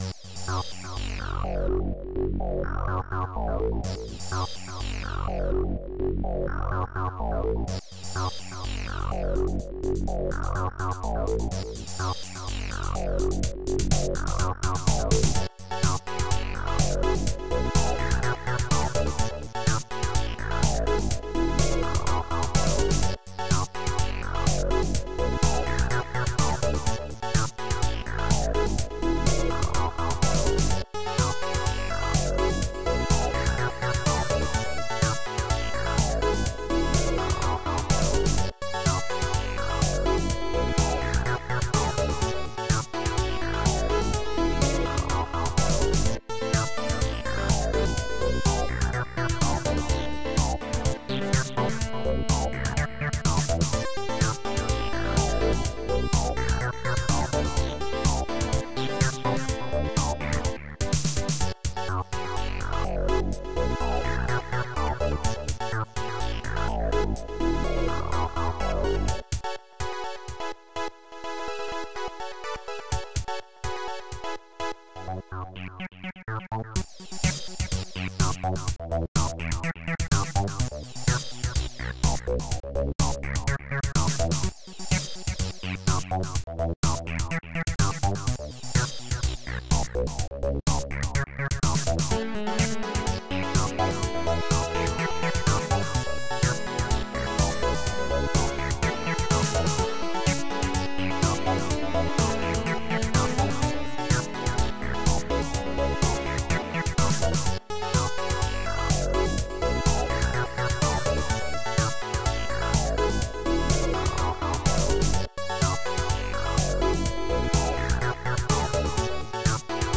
the music is a melding of electronic, techno, and rock.